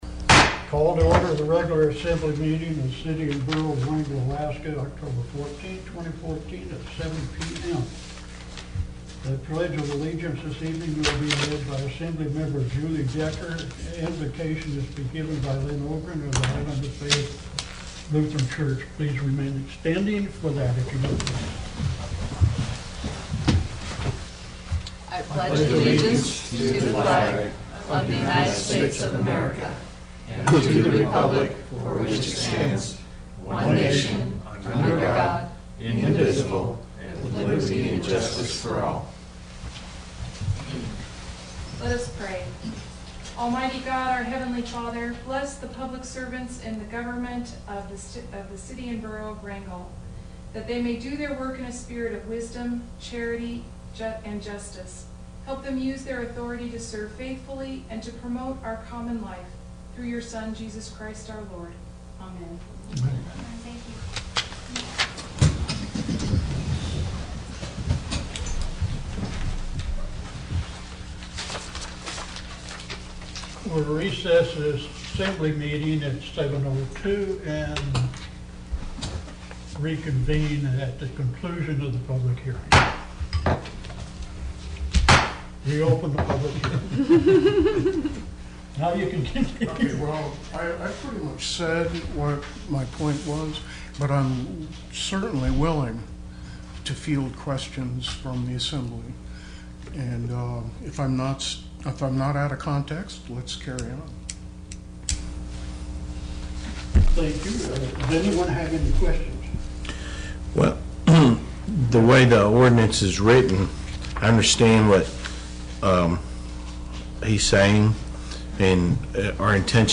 Wrangell's Borough Assembly met for a regular meeting Tuesday, Oct. 14 in the Assembly Chambers.
City and Borough of Wrangell Borough Assembly Meeting AGENDA October 14, 2014–7 p.m. Location: Assembly Chambers, City Hall